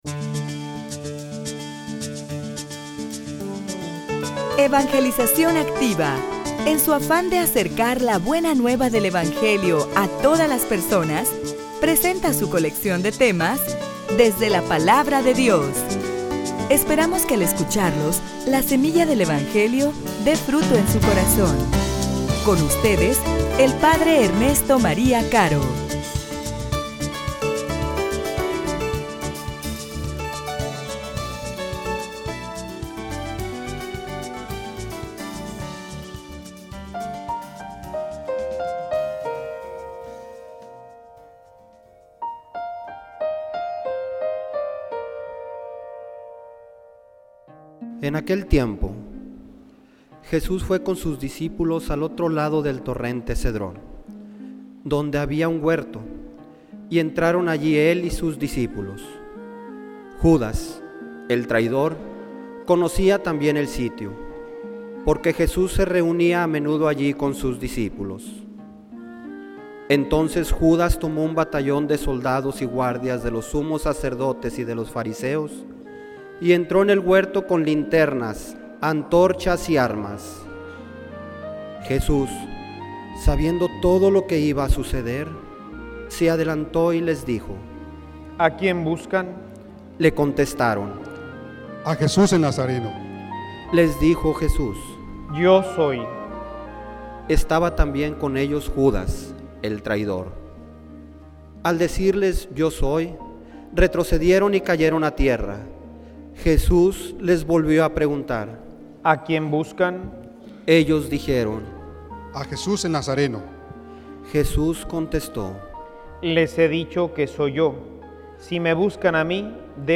homilia_La_cruz_El_Amen_del_Hombre_a_Dios.mp3